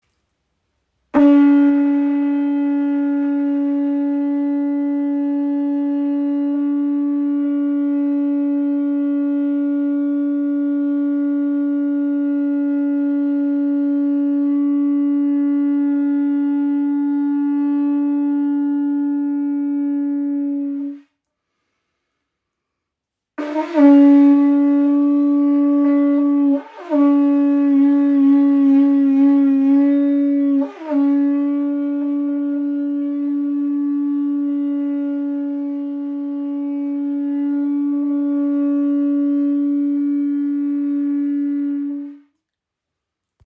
Kuhhorn, Signalhorn
Klangbeispiel
Dieses authentische Stück verkörpert die tiefe Verbundenheit mit der Vergangenheit und bringt einen kräftigen, durchdringenden und tragenden Klang hervor.
Kräftiger Klang der weit herum hörbar ist